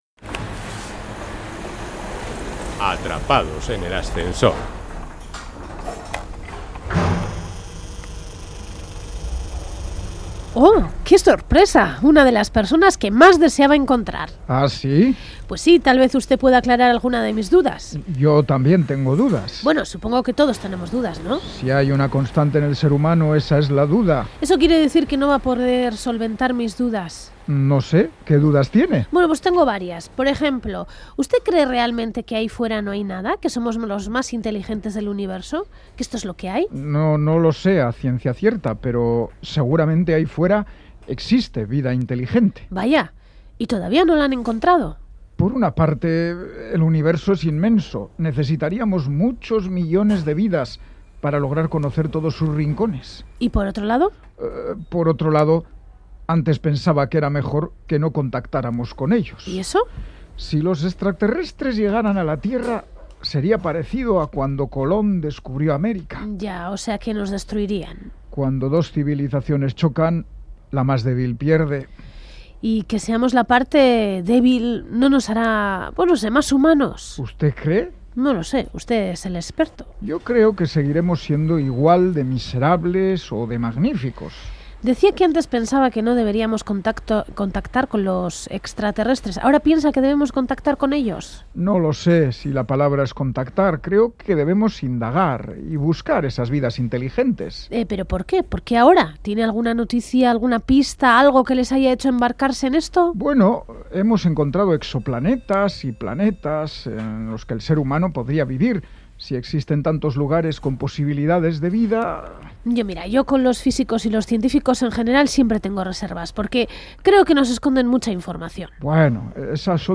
Una semana más entra en nuestro ascensor una persona famosa con la que tendremos una agradable conversación.